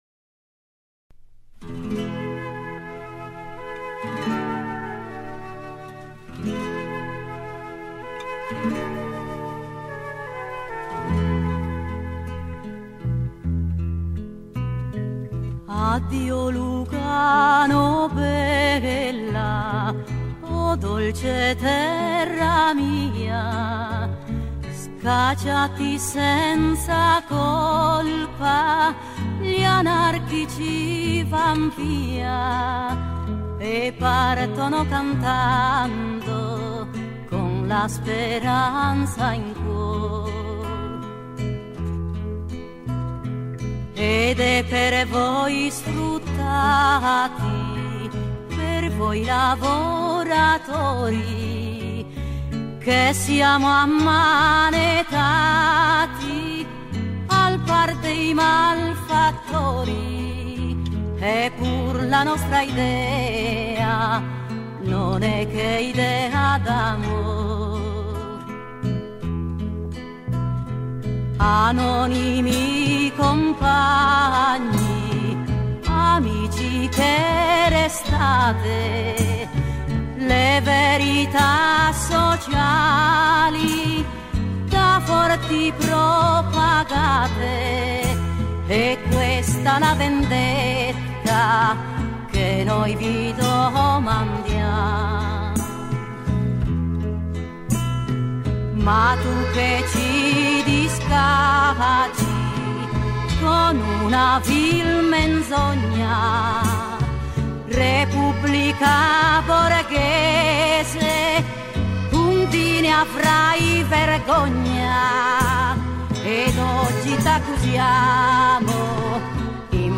Un canto contro la guerra della tradizione anarchica cantato nel corso della serata.
Maria Carta - fonte YouTube